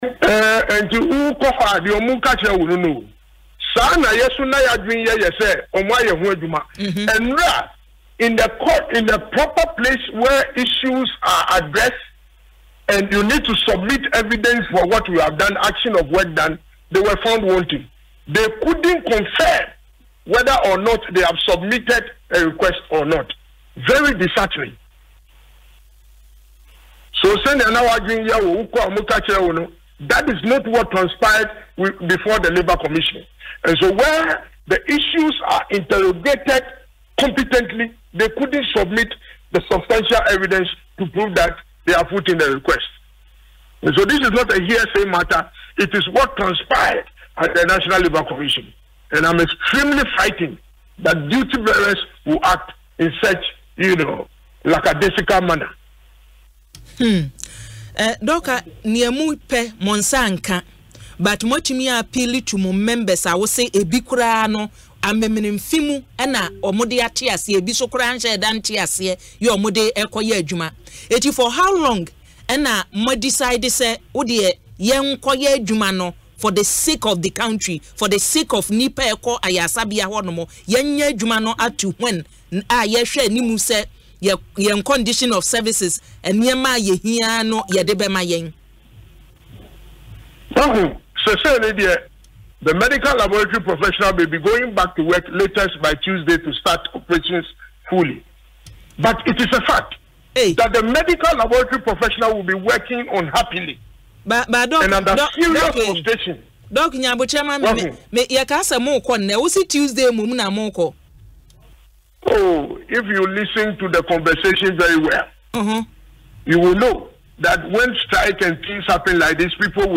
on Asempa FM’s Ekosii Sen programme Friday